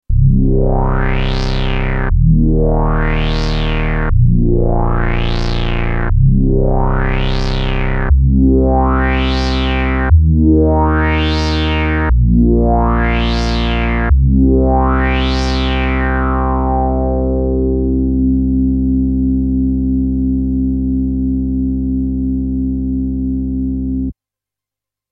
SweepBass.mp3